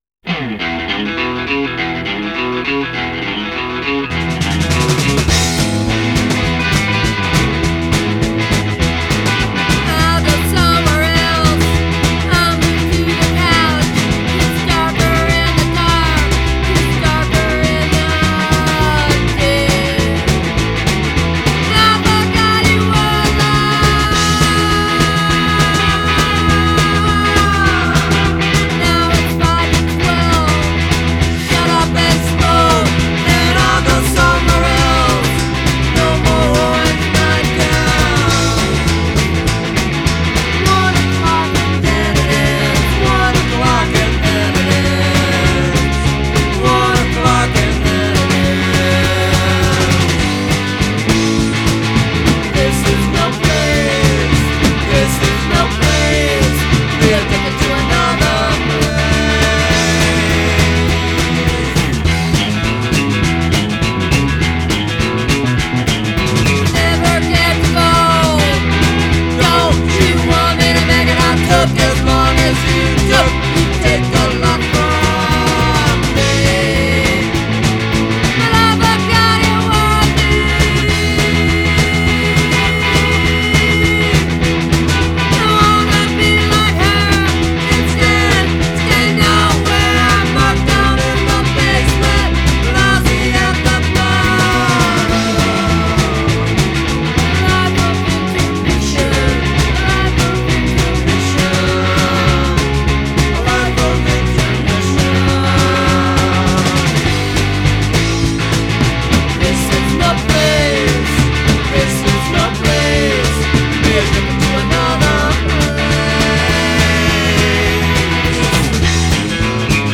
Жанр: Punk-Rock, Rockabilly, Folk-Rock, Alternative Rock